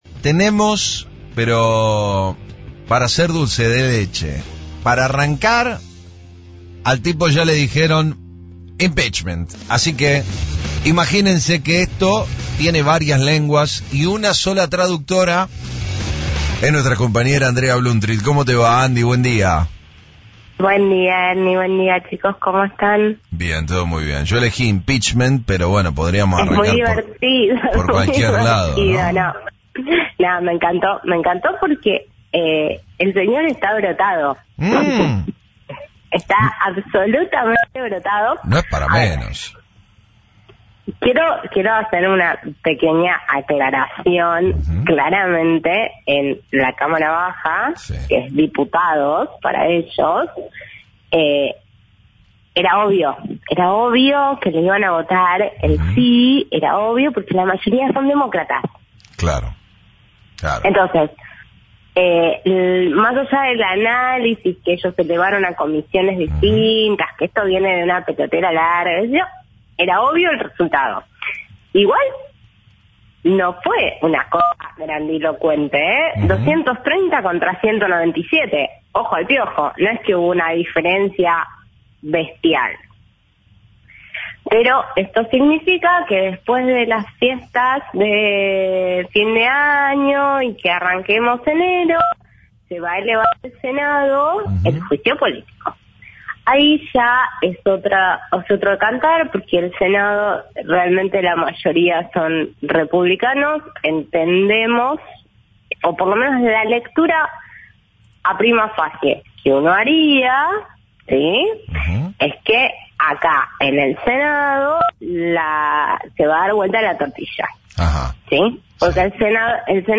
Si bien puede revertirse el panorama del presidente, la especialista sostuvo, en diálogo con FRECUENCIA ZERO , que logrará revertir el juicio en el Senado, teniendo en cuenta que los demócratas necesitan 20 escaños opositores para lograr imponerse.